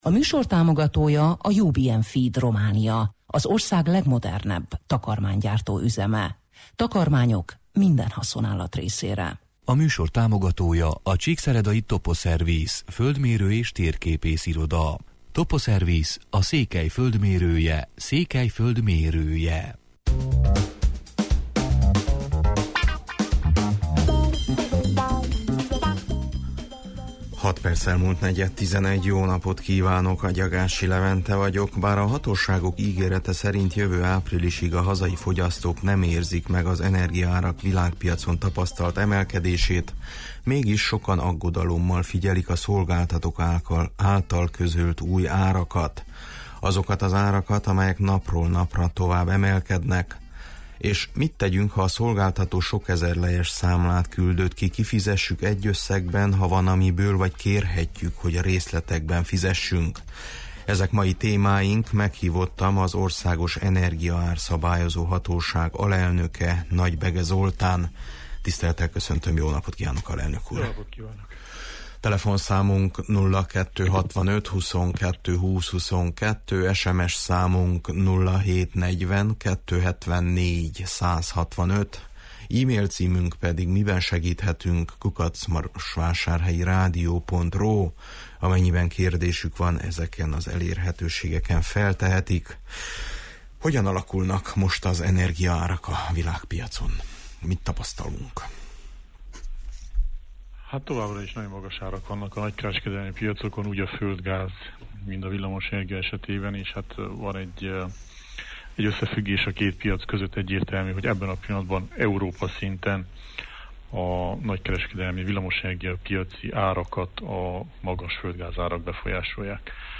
Mai meghívottam az Országos Energiaár-szabályozó Hatóság alelnöke, Nagy-Bege Zoltán: